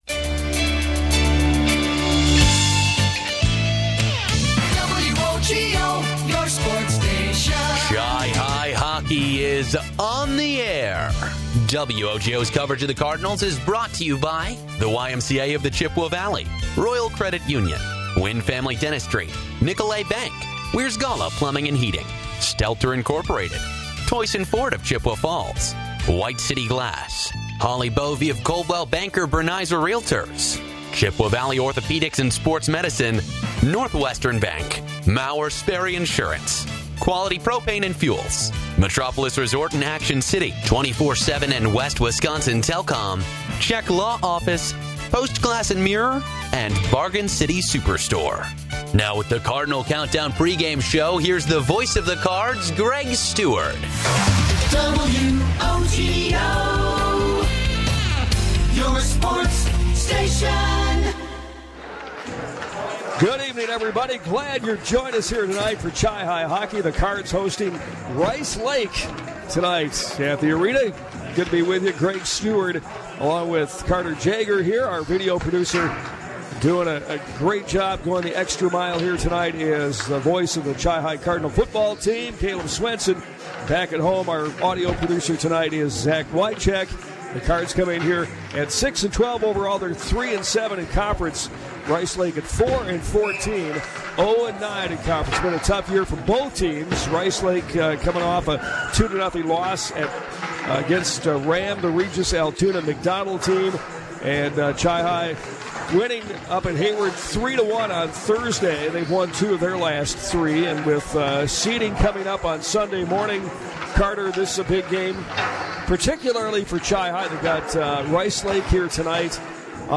with the call from the Chippewa Ice Arena